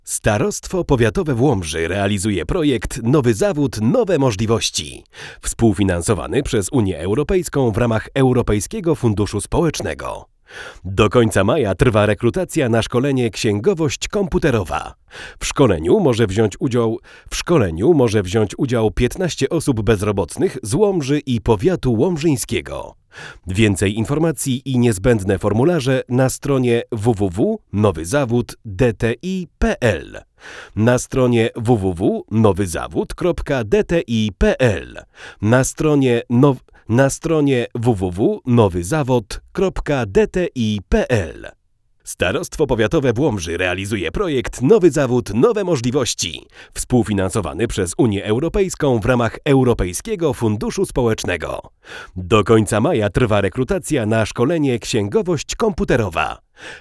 Neumann U89 + UAudio 710